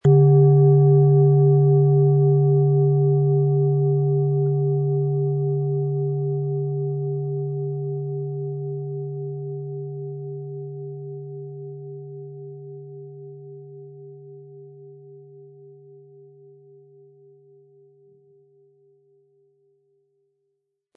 Planetenton
Im Sound-Player - Jetzt reinhören können Sie den Original-Ton genau dieser Schale anhören.
Der passende Klöppel ist kostenlos dabei, der Schlegel lässt die Klangschale harmonisch und wohltuend anklingen.
MaterialBronze